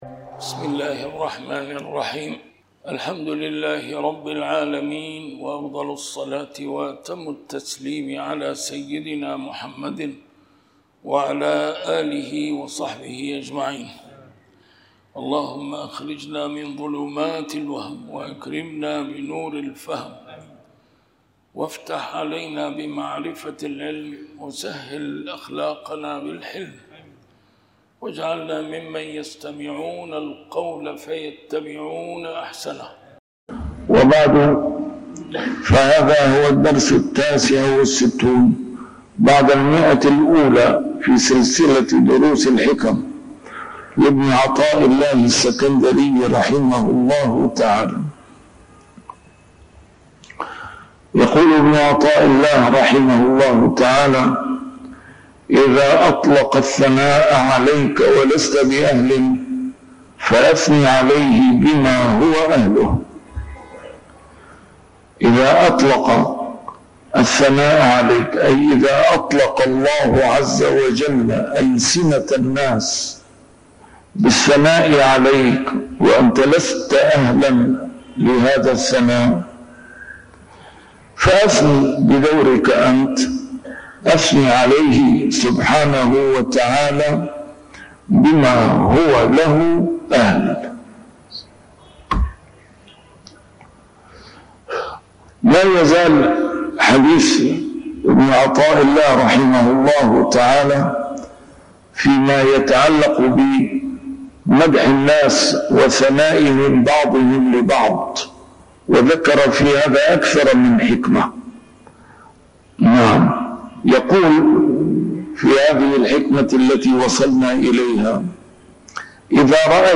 A MARTYR SCHOLAR: IMAM MUHAMMAD SAEED RAMADAN AL-BOUTI - الدروس العلمية - شرح الحكم العطائية - الدرس رقم 169 شرح الحكمة 145